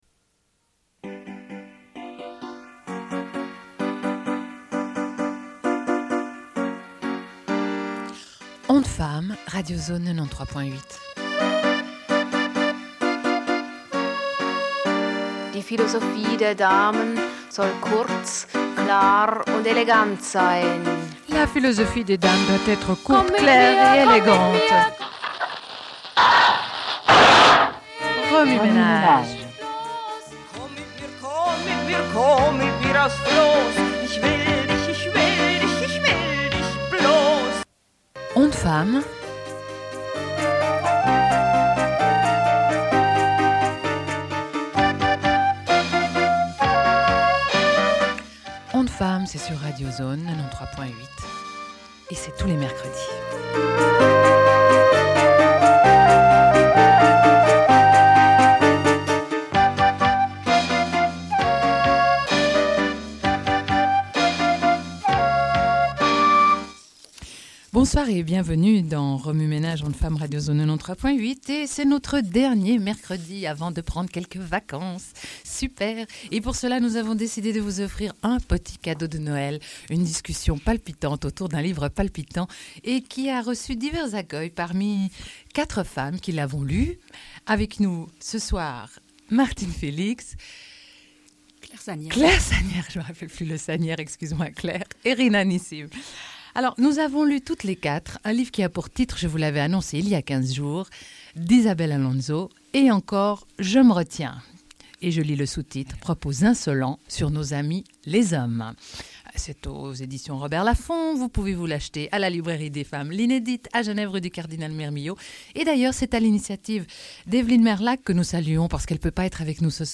Deuxième partie d'émission, rencontre avec Les Reines prochaines, à la Cave 12 le 14 décembre 1995.
Radio Enregistrement sonore